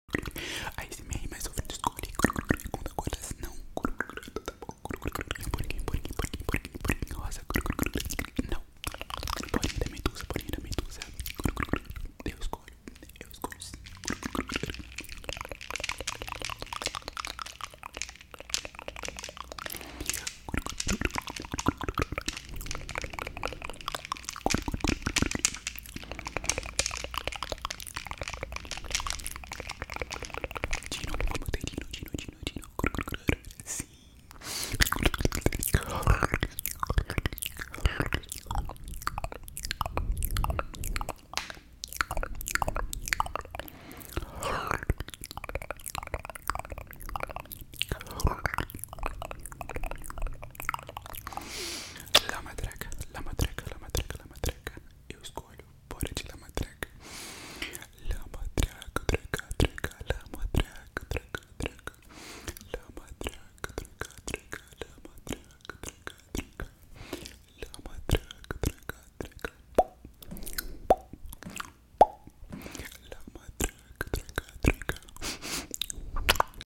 Asmr mas é o filtro